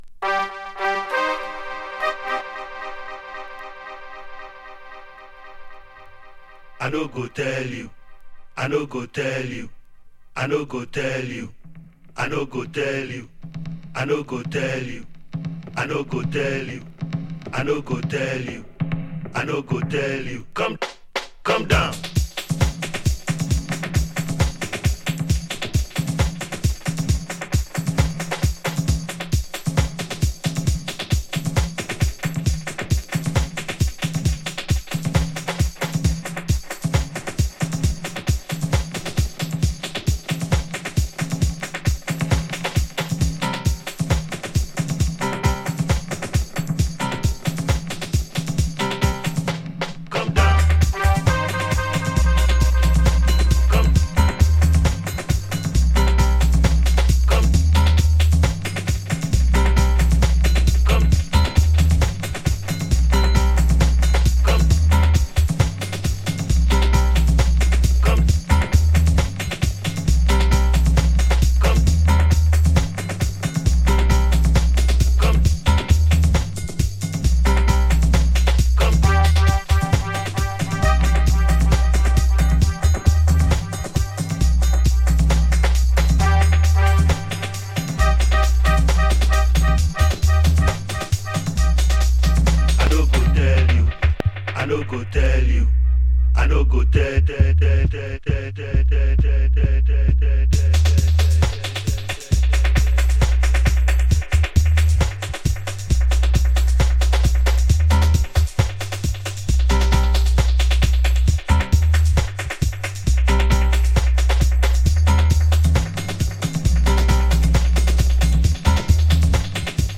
New Release Reggae / Dub Steppers